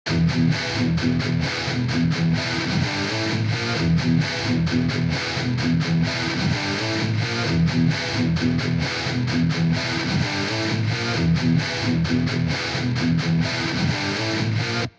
Гитары залезают в моно
Проблема в том,что как мне кажется, гитары залезают в моно.
Кидаю пример, 10 гитар, 100% панорама. гнать к 80 смысла не было, т.к и так много гитар.